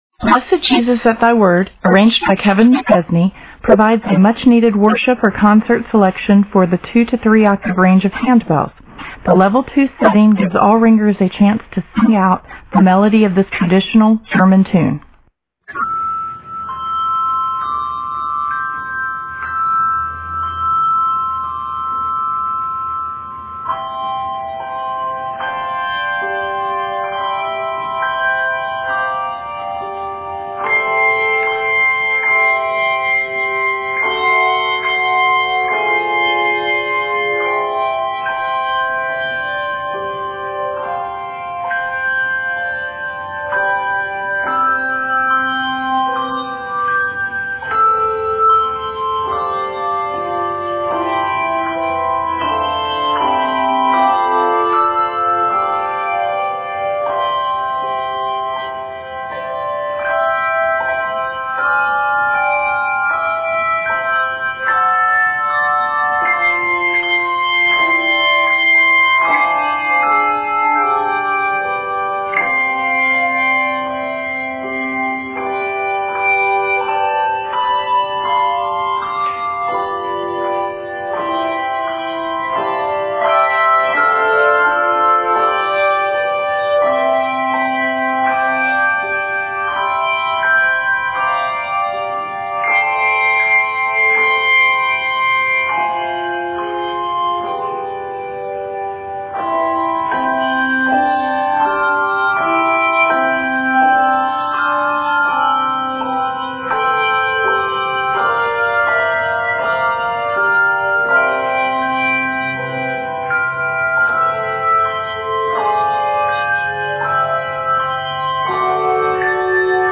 set for 2-3 octaves